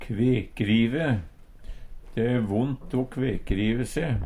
kvekrive rift i fingen etter å ha rivi av ein negl Infinitiv Presens Preteritum Perfektum å kvekrive riv reiv rivi Eksempel på bruk De e vondt o kvekrive se. Høyr på uttala Ordklasse: Verb Kategori: Kropp, helse, slekt (mennesket) Attende til søk